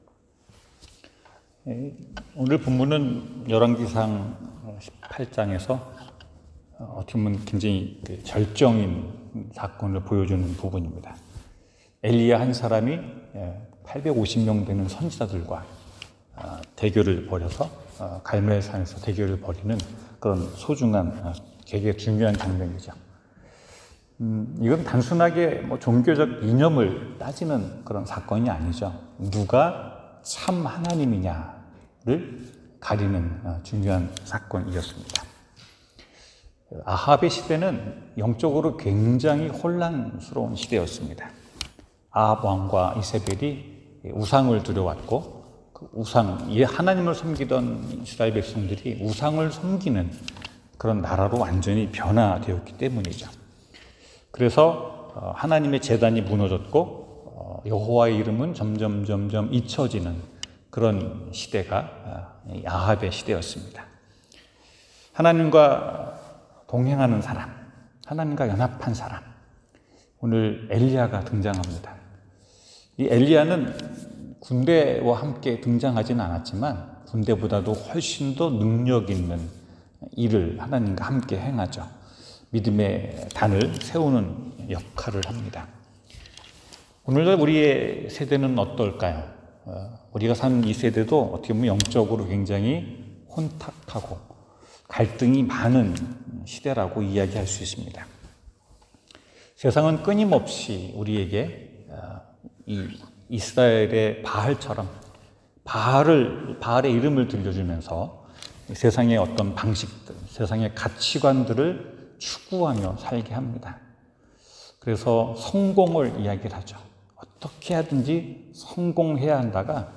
하나님과 연합할 때 성경: 열왕기상 18:30-40 설교